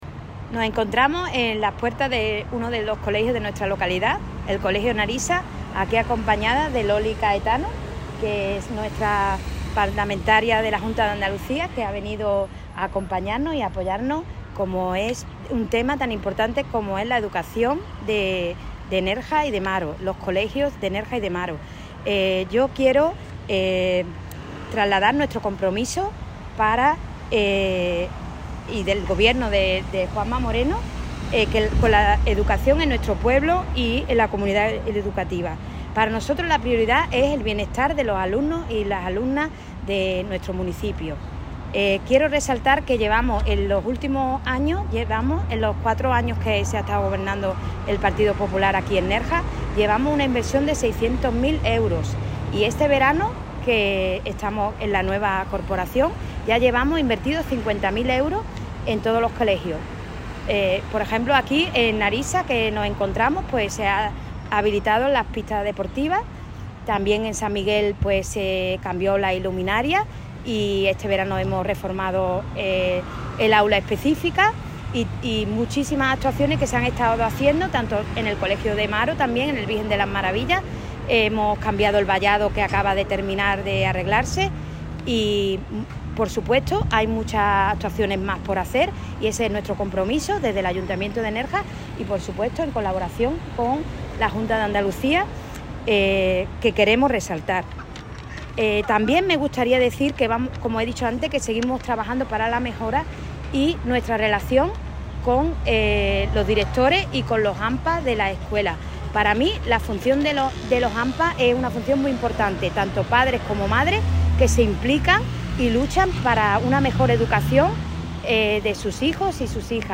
Así lo ha expuesto durante una visita a Nerja, destacando que “la inversión por alumno ha crecido un 40% respecto al último gobierno socialista, al destinarse 2.200 millones de euros más que entonces”, marco en el que ha subrayado que “la educación pública andaluza cuenta hoy con 6.500 profesores y maestros más que en 2018, estando además mejor pagados gracias al complemento aprobado para la equiparación salarial con el resto de comunidades autónomas”.